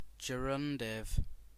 Ääntäminen
IPA : /dʒəˈrʌndɪv/